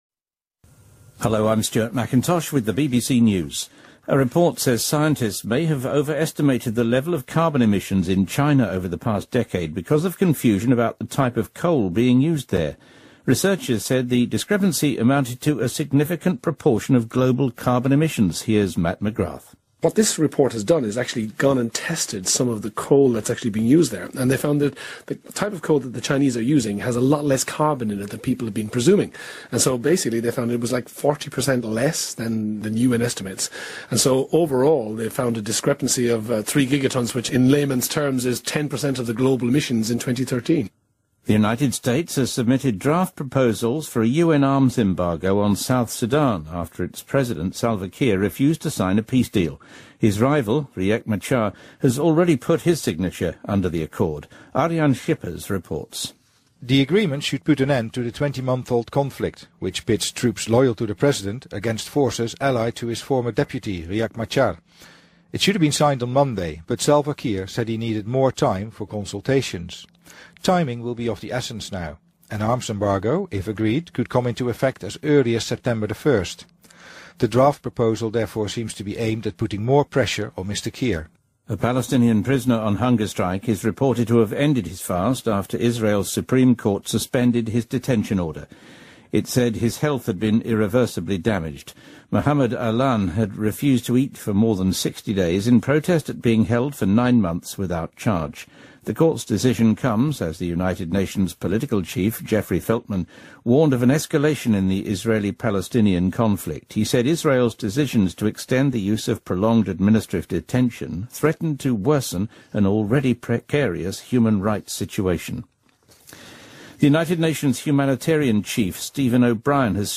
BBC news,法国检察官撤销对卢旺达罗马天主教牧师的种族屠杀指控